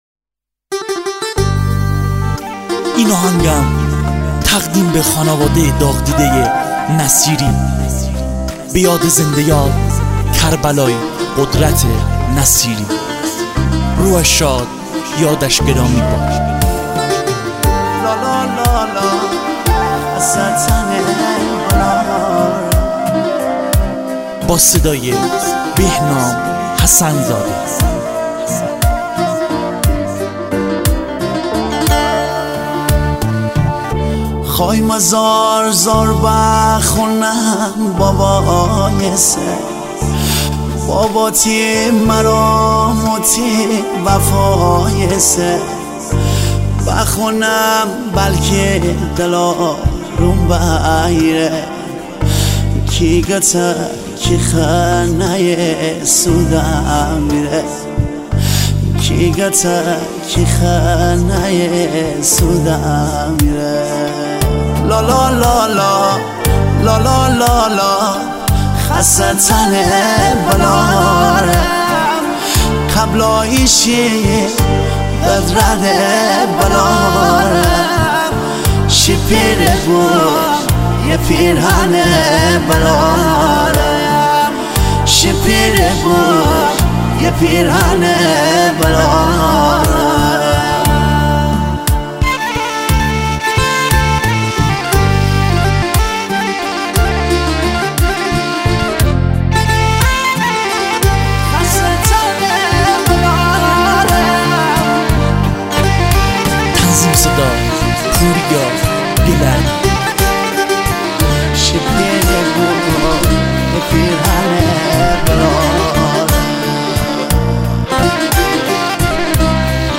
آهنگ مازندرانی عالی هست